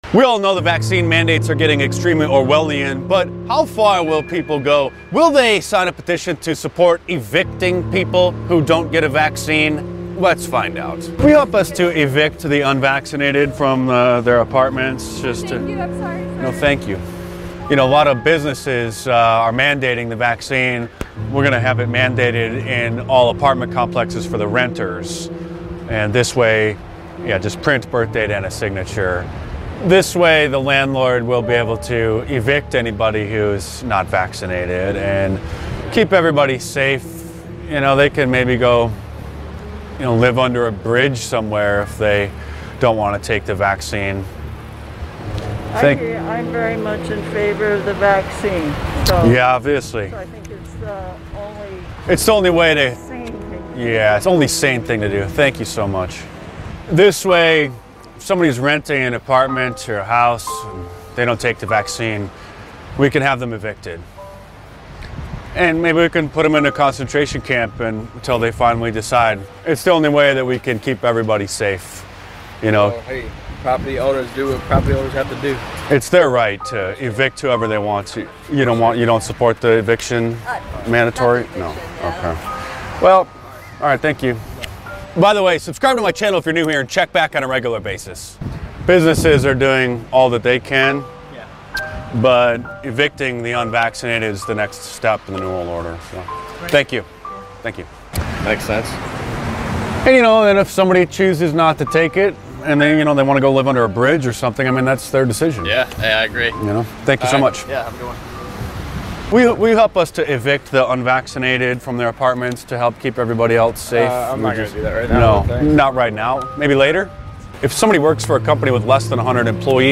In a shocking experiment, random Americans are asked if they’ll sign a petition to evict anyone from their home if they refuse to take the COVID-19 vaccine.
Filmed in San Diego, California.